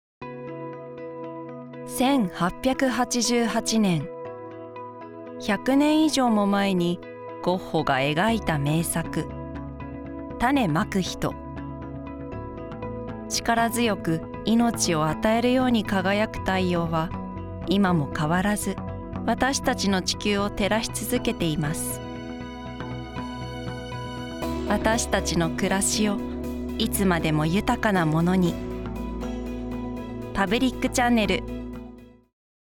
ボイスサンプル